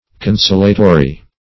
Consolatory \Con*sol"a*to*ry\, a. [L. consolatorius.]
consolatory.mp3